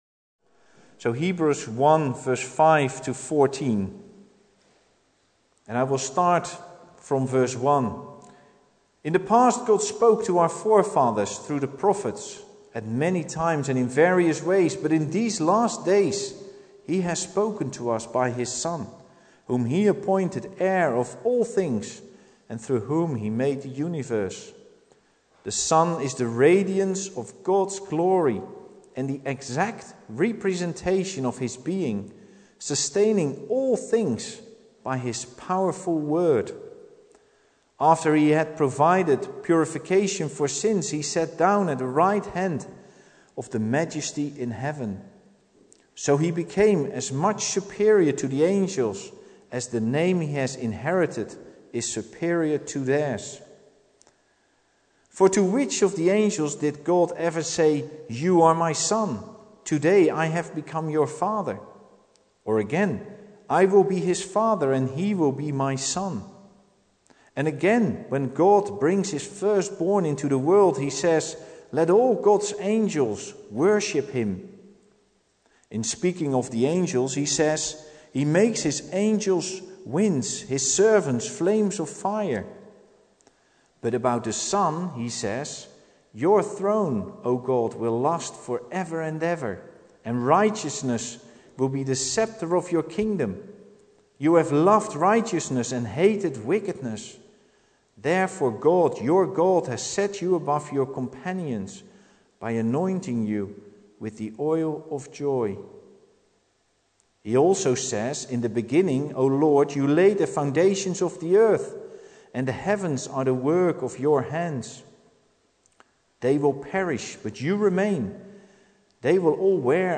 Service Type: Sunday Evening
Part recording